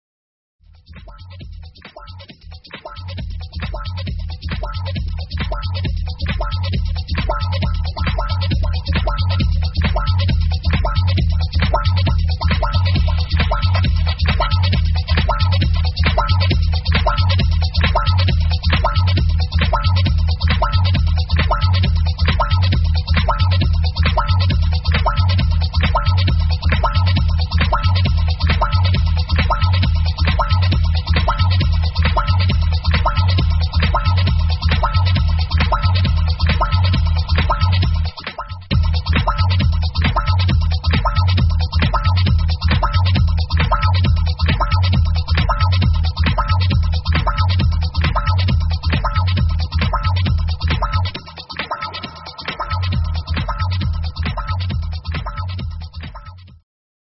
great minimal/techno/house(?) tune!!!!